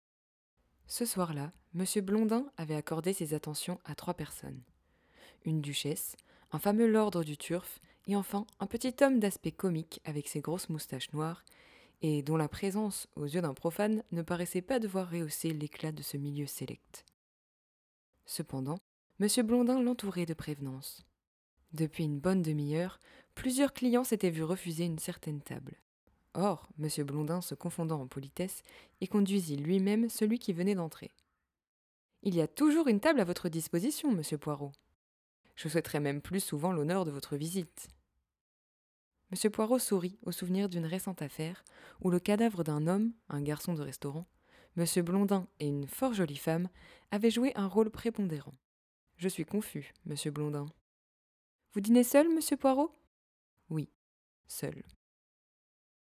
Voix Off - Mort sur le Nil, Agatha Christie
- Mezzo-soprano